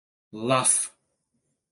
Prononcé comme (IPA) /lɑf/